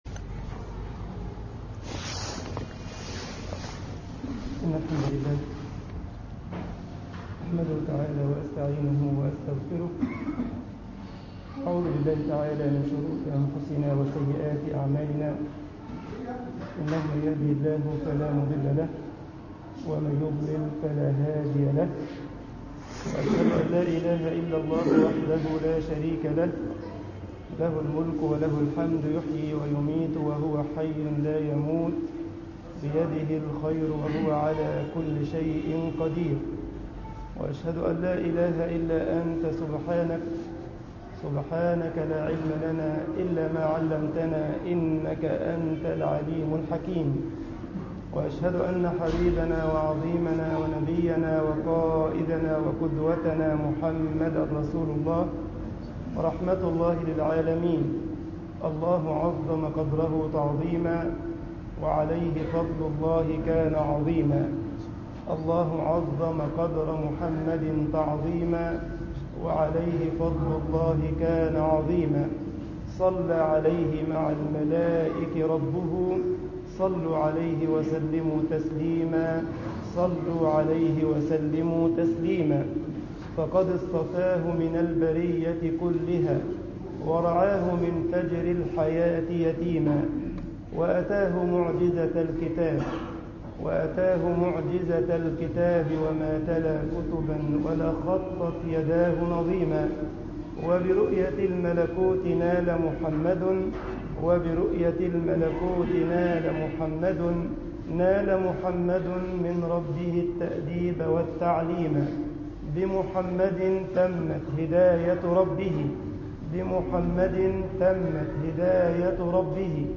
مسجد عمر بن الخطاب ـ الجيزة محاضرة